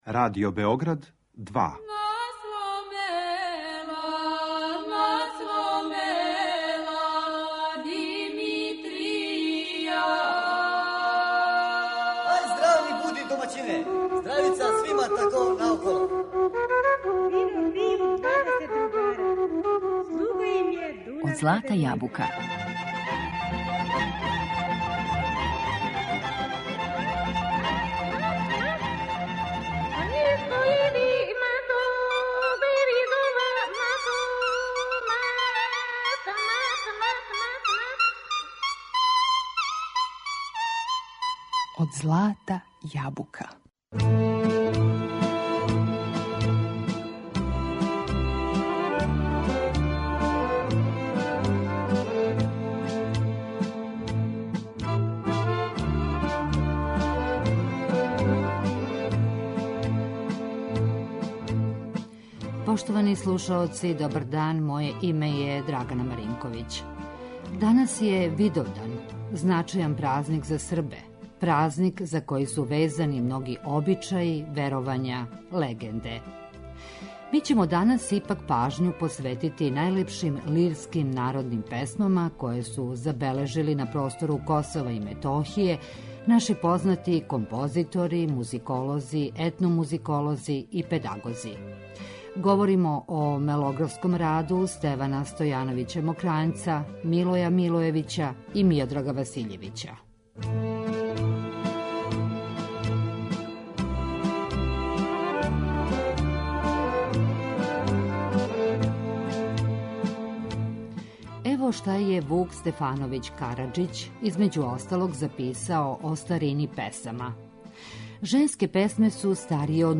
Лирске народне песме